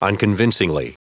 Prononciation du mot unconvincingly en anglais (fichier audio)
Prononciation du mot : unconvincingly